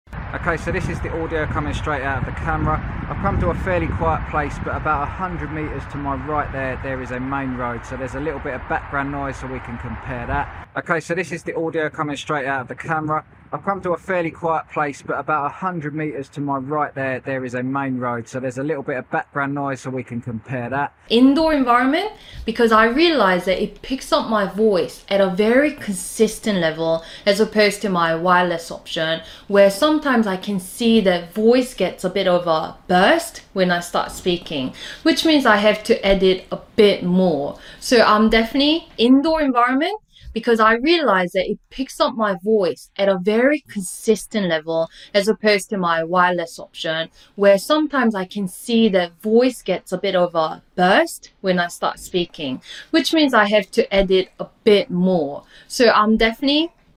هندسة صوتية وازالة الضوضاء والتشويش وتنقية الصوت باحترافية 1